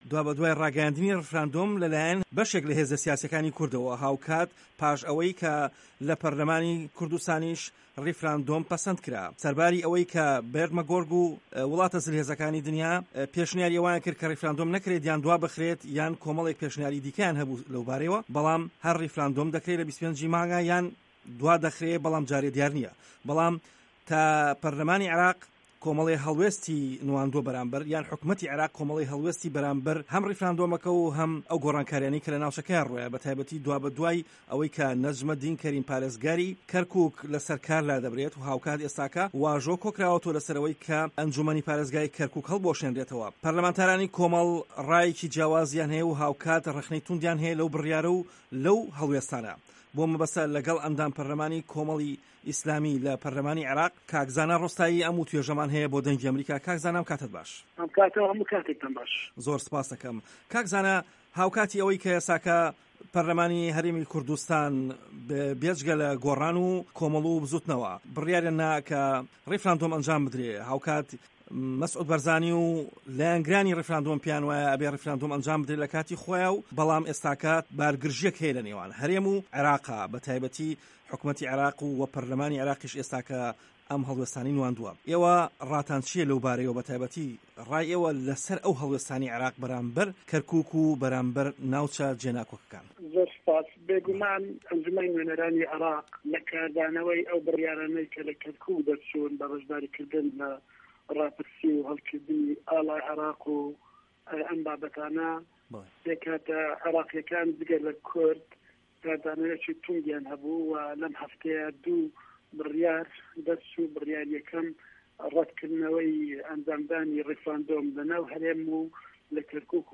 وتووێژی زانا ڕۆستایی